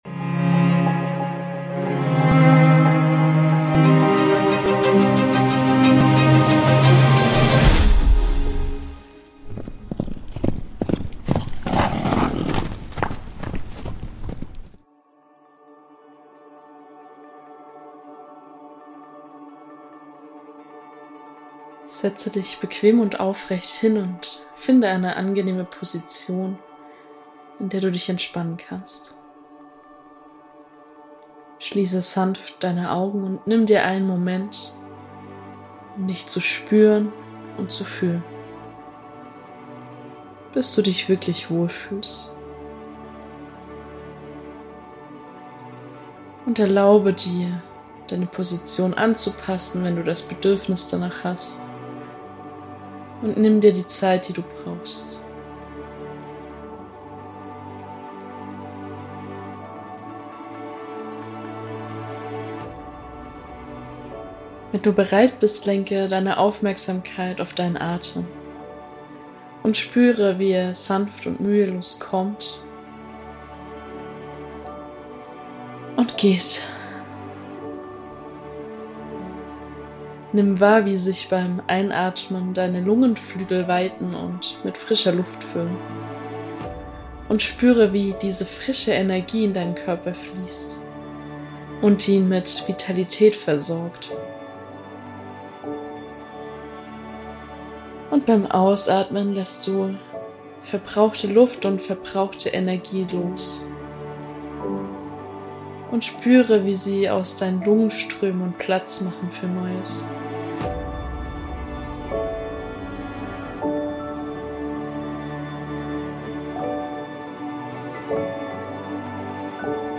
Eine Meditation für mehr inneren Frieden.